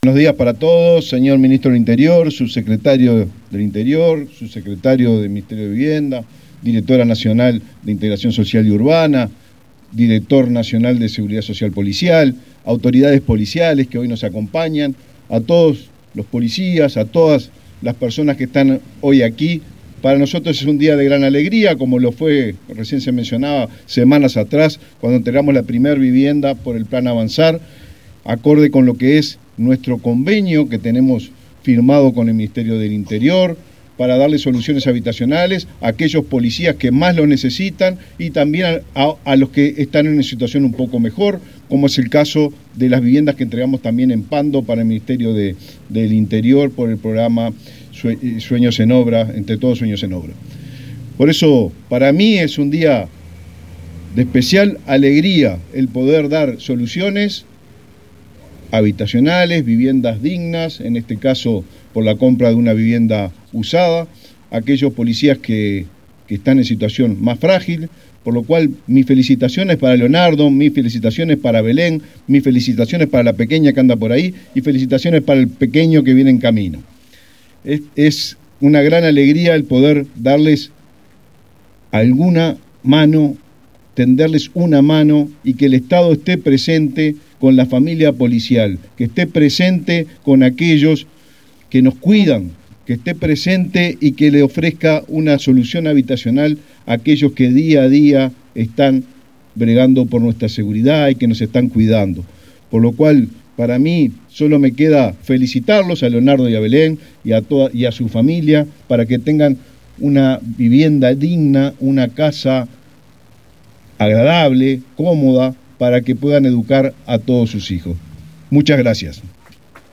Palabras del ministro de Vivienda, Raúl Lozano
El ministro de Vivienda, Raúl Lozano, participó, este martes 2 en Montevideo, de la inauguración de viviendas del Plan Avanzar.